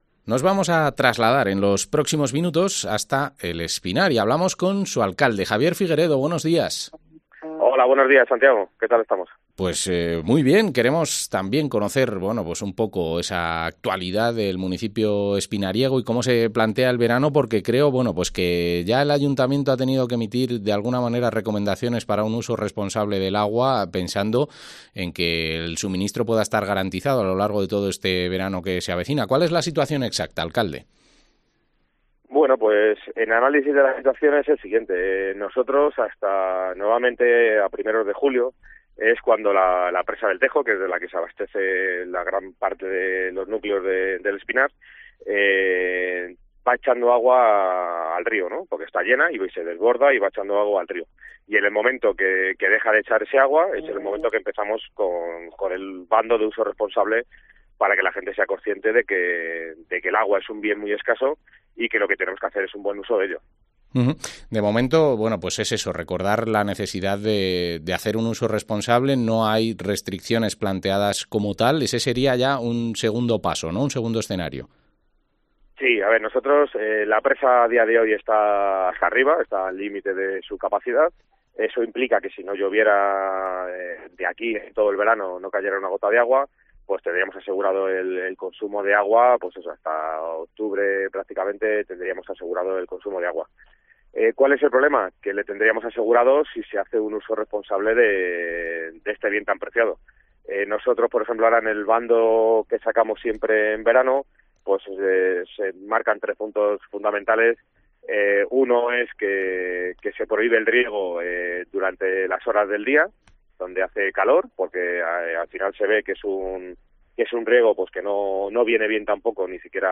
Entrevista al alcalde de El Espinar, Javier Figueredo